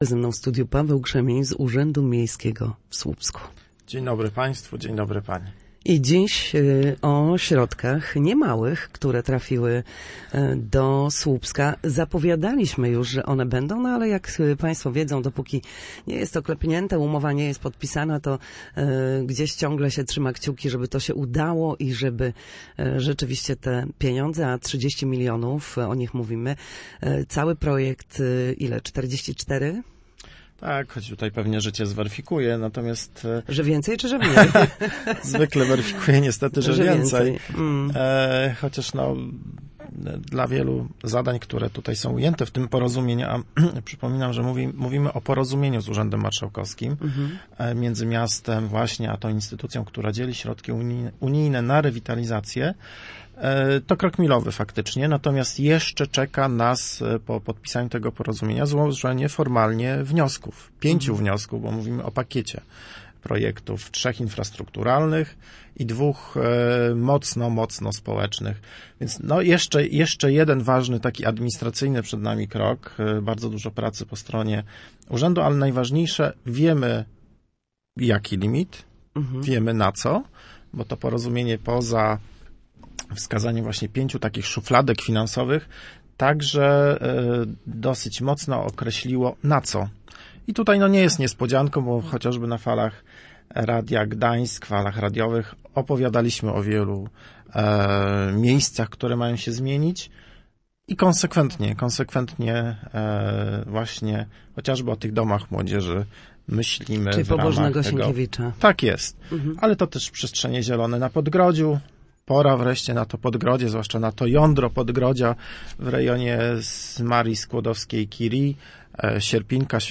Jak mówił w Studiu Słupsk Radia Gdańsk